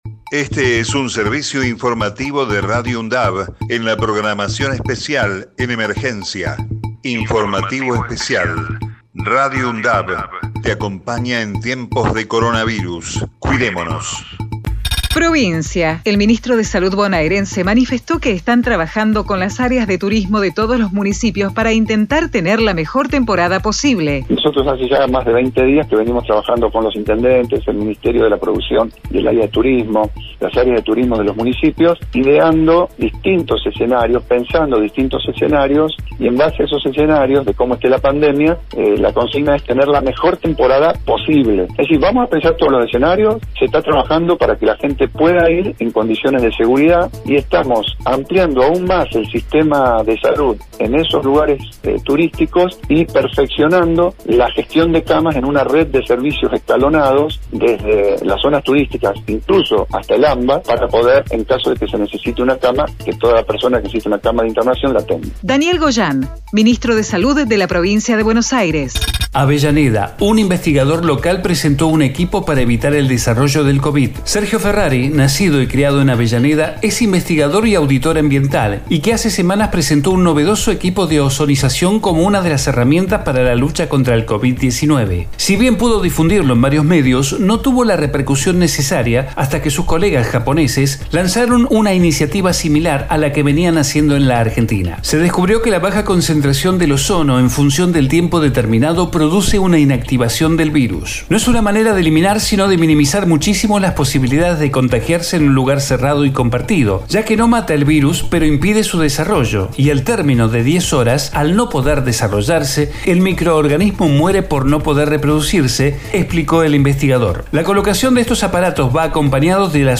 COVID-19 Informativo en emergencia 28 de septiembre 2020 Texto de la nota: Este es un servicio informativo de Radio UNDAV en la programación especial en emergencia.